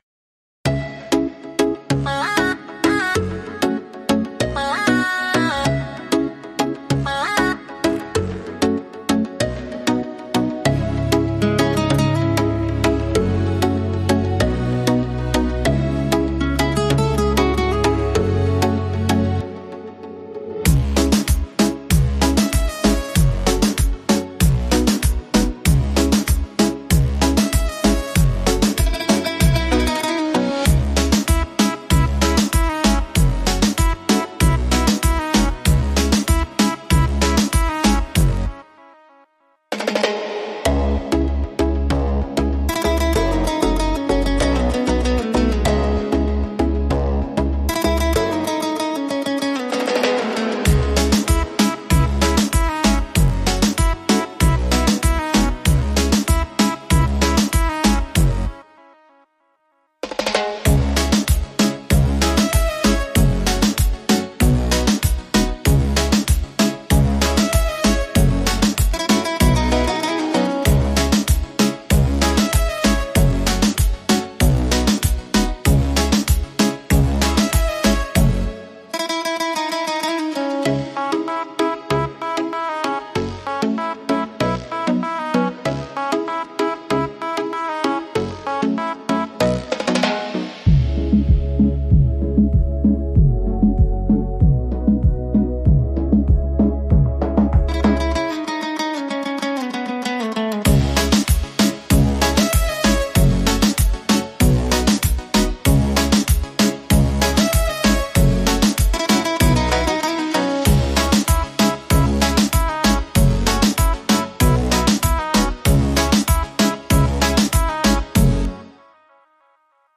Beat Reggaeton Instrumental
Acapella e Cori Reggaeton Inclusi
C#m